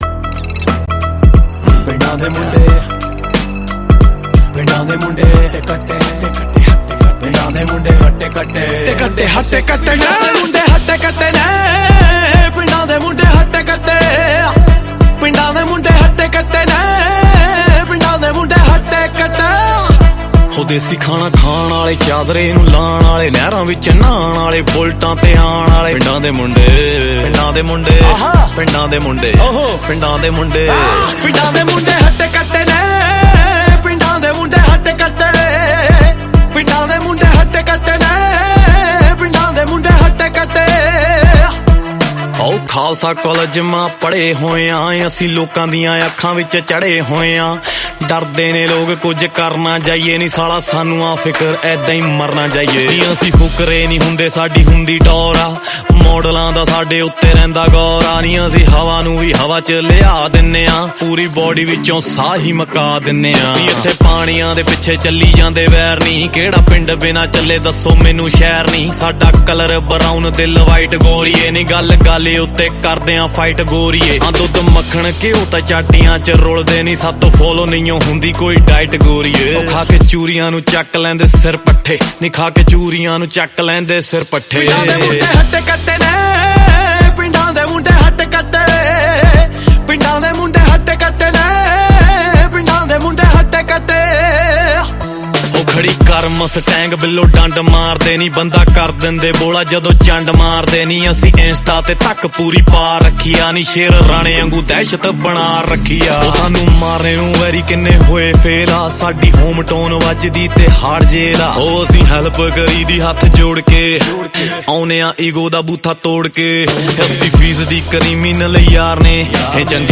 Punjabi Single Track song